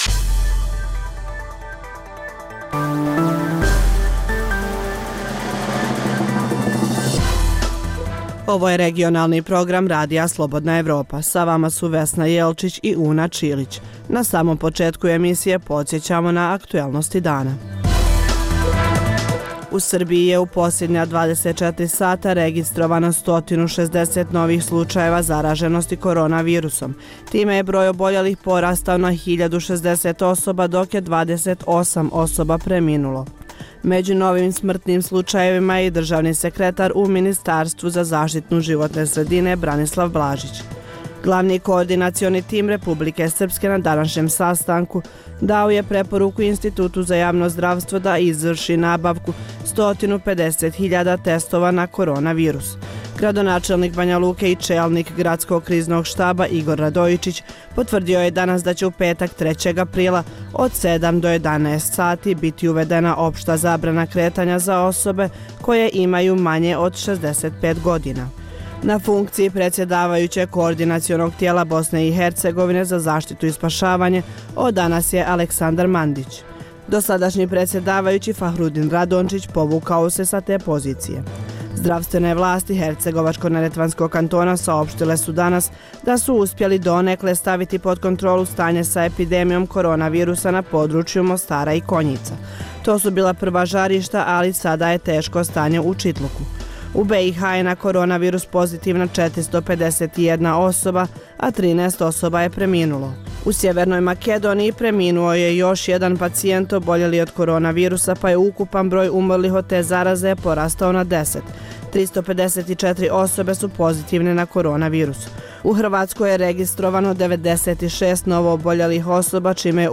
Dnevna informativna emisija Radija Slobodna Evropa o događajima u regionu i u svijetu.
Za Radio Slobodna Evropa govori Karl Bilt, švedski političar i diplomata i kopredsjedavajući Evropskog savjeta za spoljne odnose.